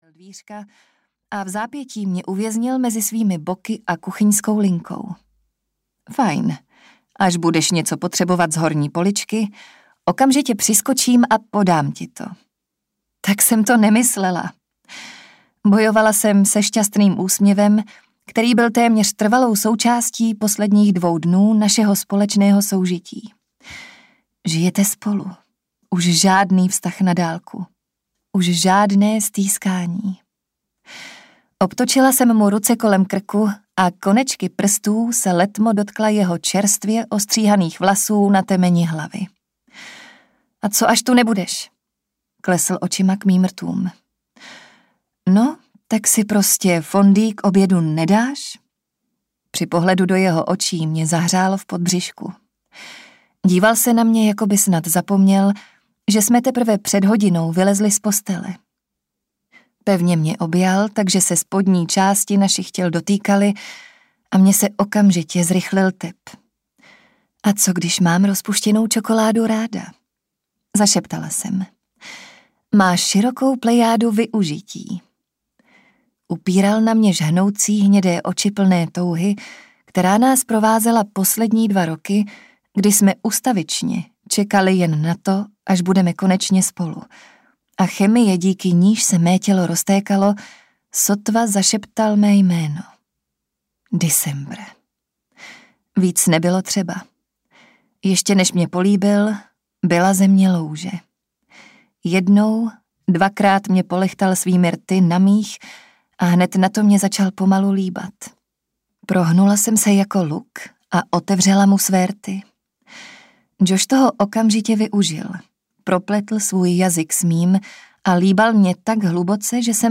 Padnout na zem audiokniha
Ukázka z knihy
padnout-na-zem-audiokniha